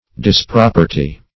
Search Result for " disproperty" : The Collaborative International Dictionary of English v.0.48: Disproperty \Dis*prop"er*ty\, v. t. To cause to be no longer property; to dispossess of.